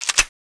boltpull.wav